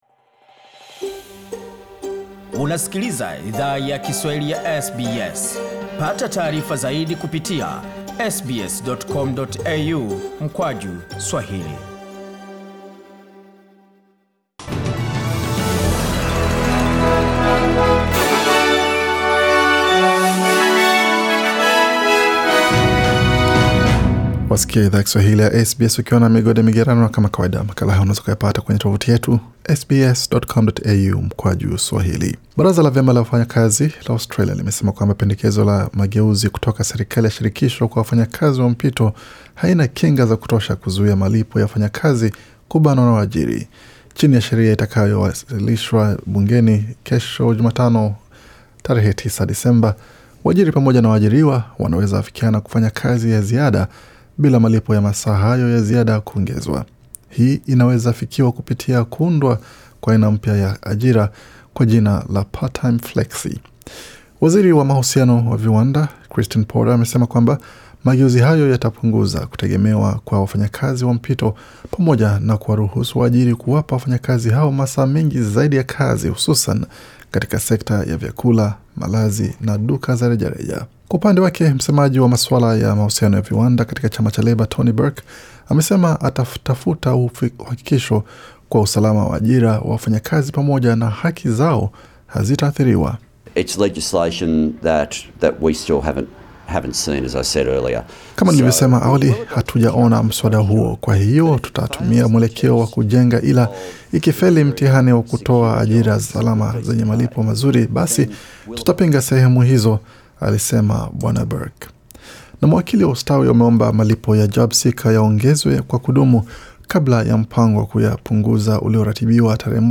Taarifa ya habari 8 Disemba 2020